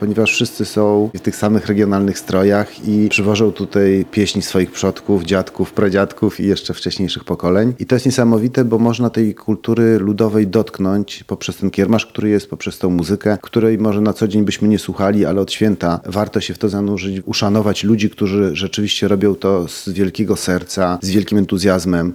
Niedziela (29.06) to ostatni dzień 59. Ogólnopolskiego Festiwalu Kapel i Śpiewaków Ludowych w Kazimierzu Dolnym. W konkursie bierze udział kilkuset wykonawców z całej Polski.